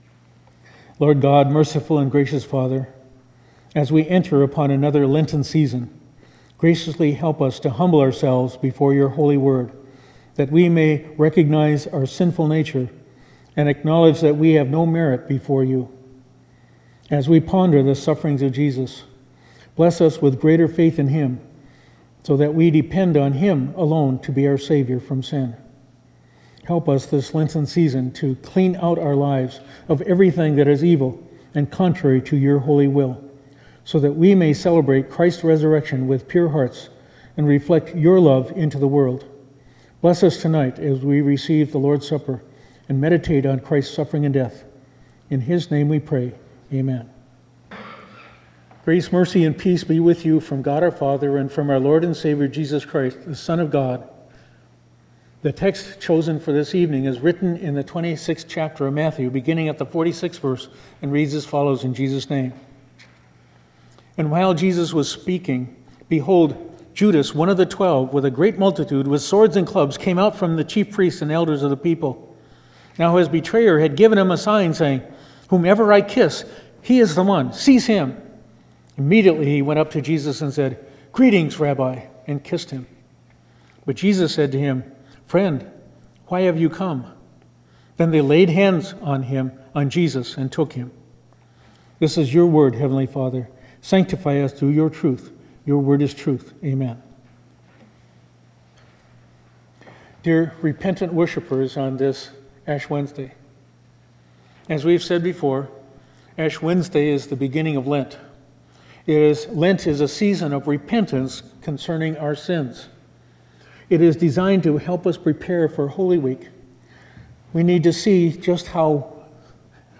Ash Wednesday 2019